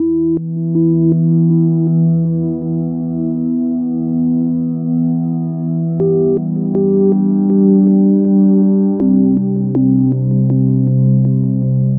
标签： 80 bpm Ambient Loops Pad Loops 1.01 MB wav Key : A
声道单声道